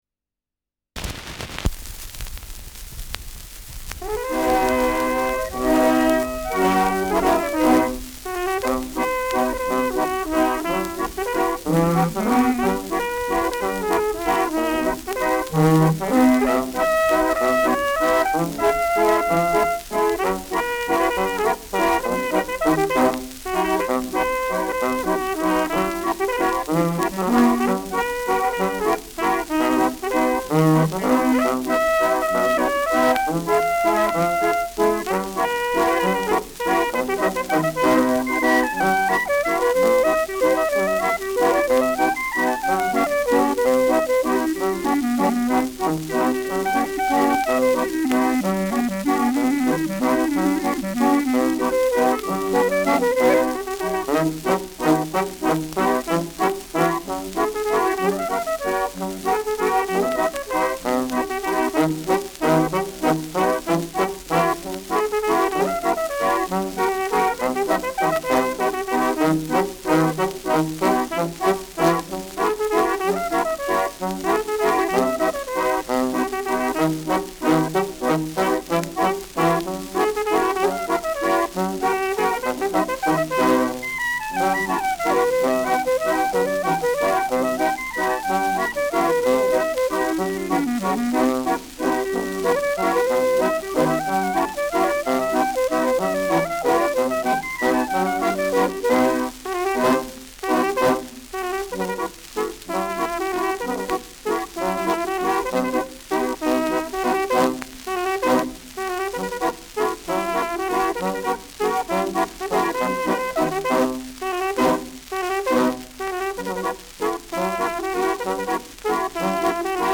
Schellackplatte
Stärkeres Grundrauschen : Gelegentlich leichtes bis stärkeres Knacken
Stadelheimer Salon-Kapelle (Interpretation)